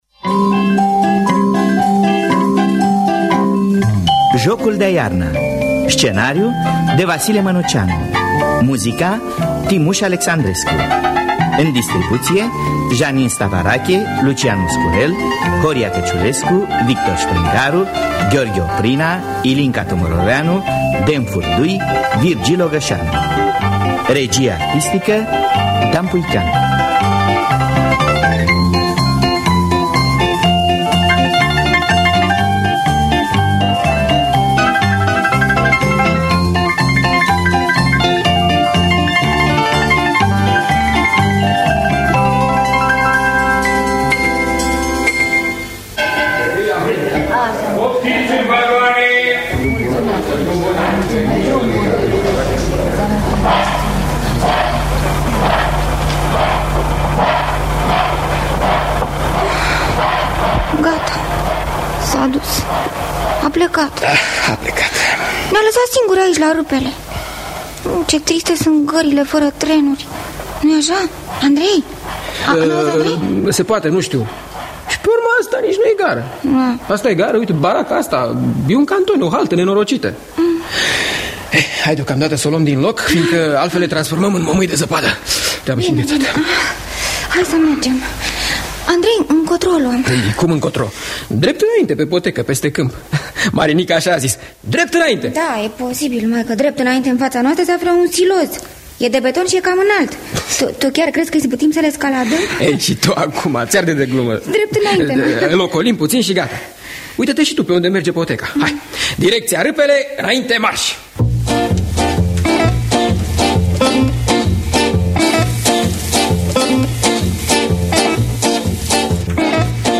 Scenariu radiofonic muzical de Vasile Mănuceanu.
Jocul de-a iarna muzical pentru copii de Vasile Manuceanu.mp3